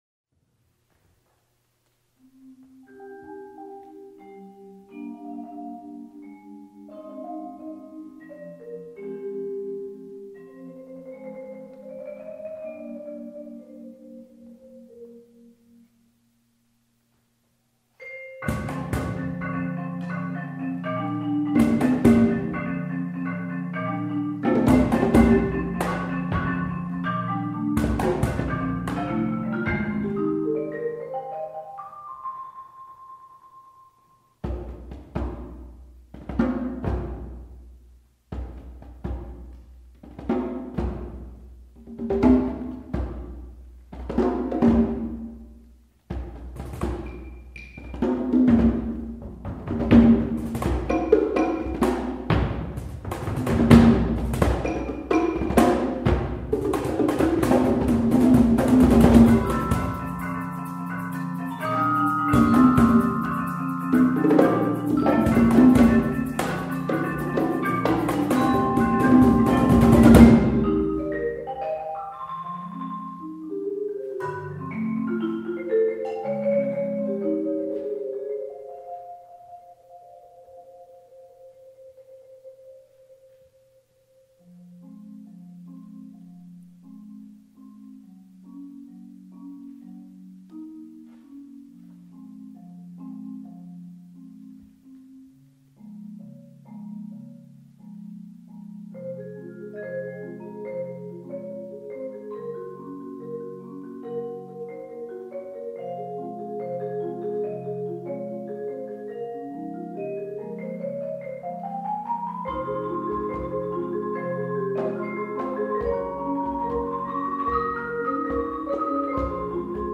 Genre: Percussion Ensemble
# of Players: 6
Player 1: Vibraphone
Player 2: Marimba (4.3-octave) [shared w/ P4]
Player 3: Bass Drum, Claves, Shaker, Marimba (4.3-octave)
Player 5: Djembe, Tambourine
Player 6: Bongos, Congas (2), Triangle